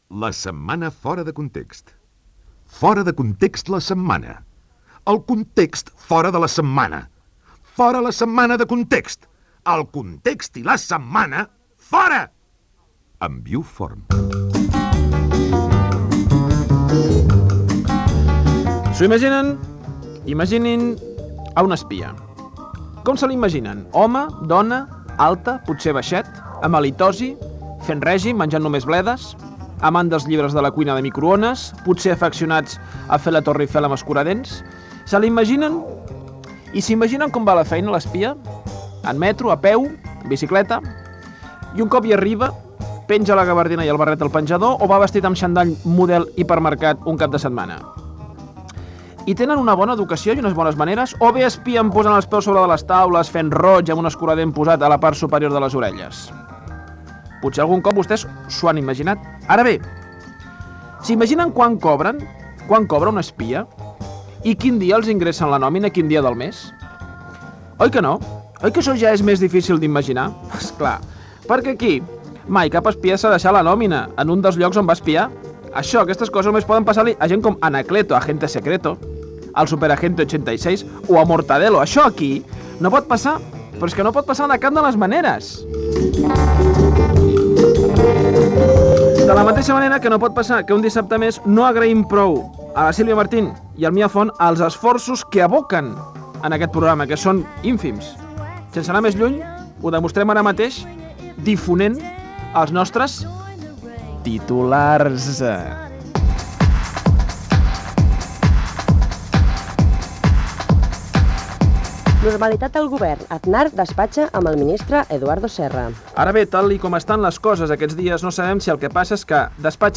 Careta del programa, comentari sobre els espies, "Els titulars": repàs humorístic a les notícies de la setmana
Entreteniment